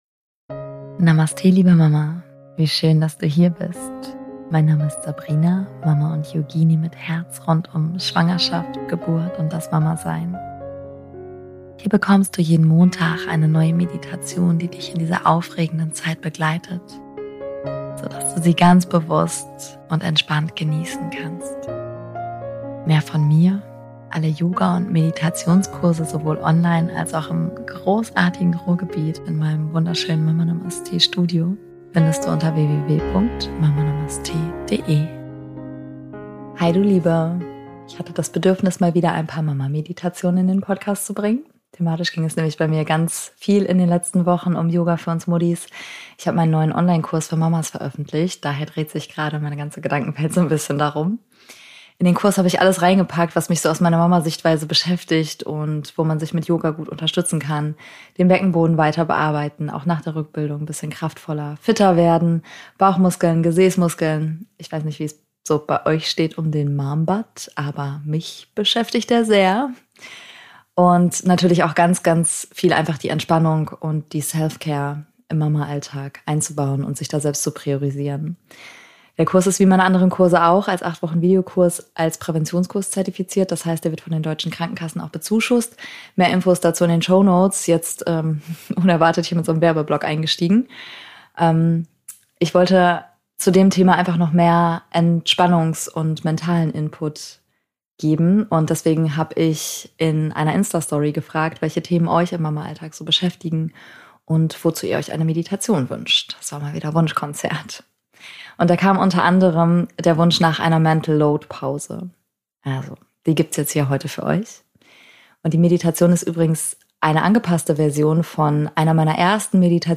Für diese Folge habe ich euch auf Instagram gefragt, welche Themen dich im Mama-Alltag gerade besonders beschäftigen – und ganz oft kam der Wunsch nach einer Pause vom Mental Load. Die Meditation ist eine angepasste Version einer meiner ersten Schwangerschaftsmeditationen hier im Podcast. Vielleicht kennst du sie noch – unsere kleine Reise an den See der Ruhe und Klarheit.